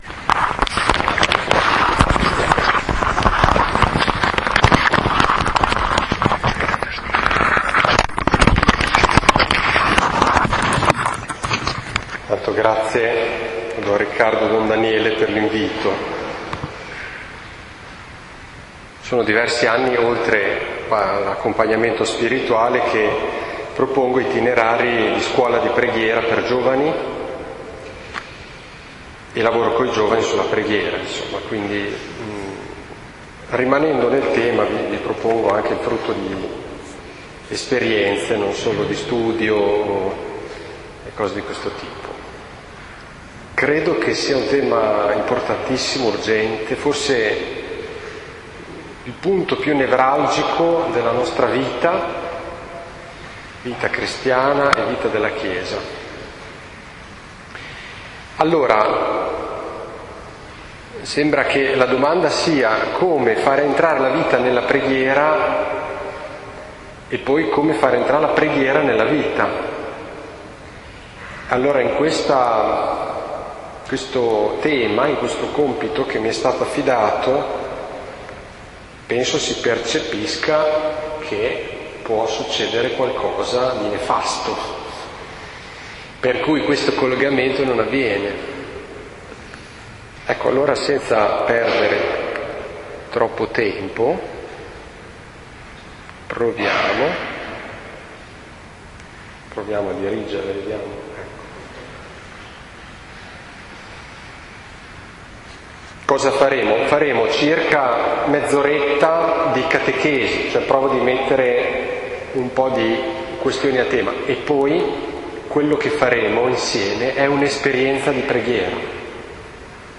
RITIRO DI AVVENTO la preghiera nella vita, la vita nella preghiera 8 dicembre, ore 16, chiesa S. Bartolomeo (leggi tutto) Registrazione del ritiro
Ritiro Avvento 2021.mp3